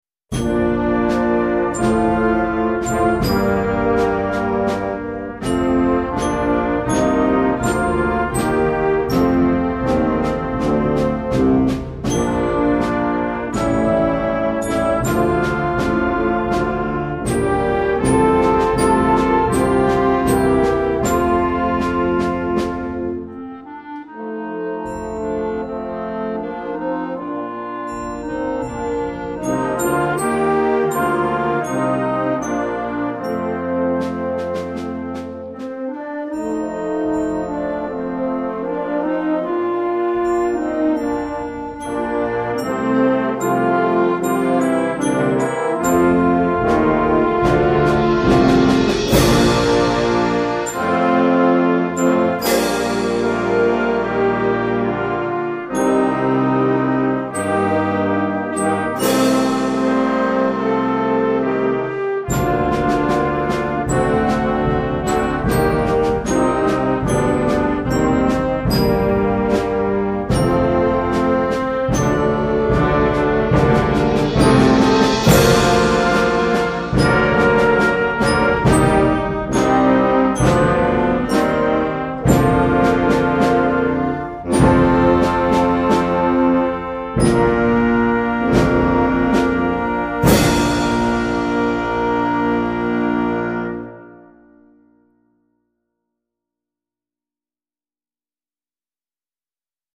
Concert Band